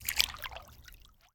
water-splash-11
bath bathroom bubble burp click drain dribble dripping sound effect free sound royalty free Nature